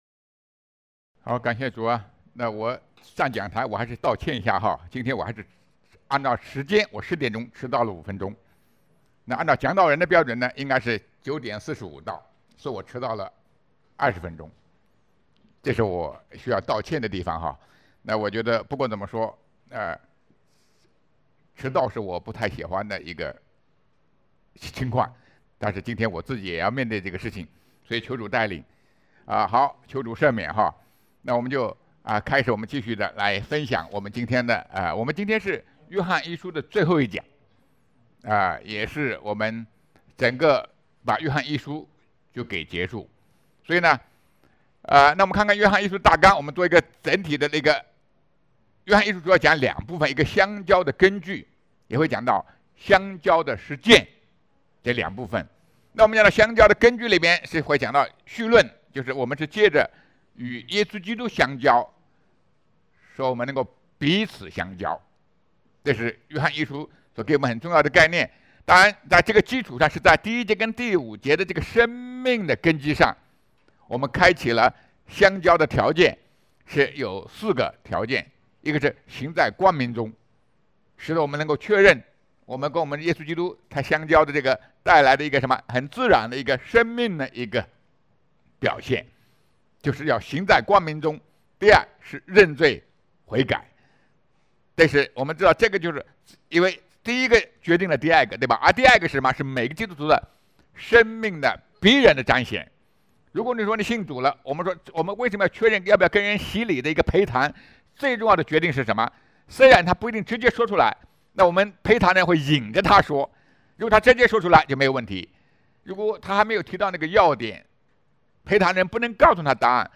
圣经讲道